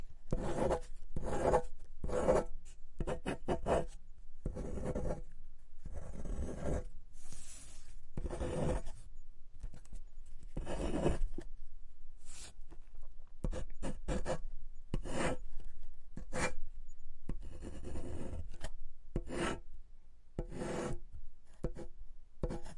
写作 " 细尖笔在纸上的玻璃线和形状
描述：记录在带有SM81和便宜的akg SDC的SD 702上，不记得哪一个只是想要变化。
没有EQ不低端滚动所以它有一个丰富的低端，你可以驯服品尝。